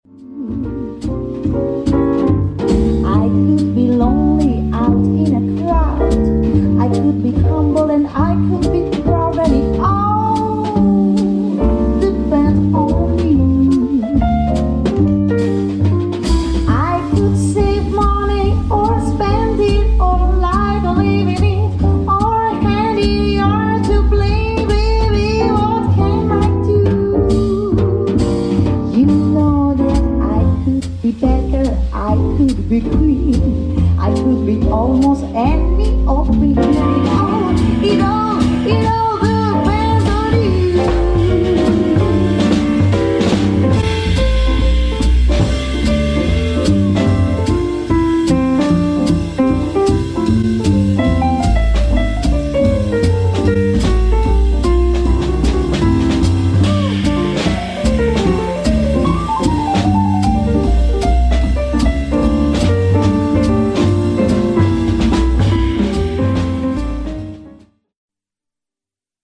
chant
piano
contrebasse
batterie.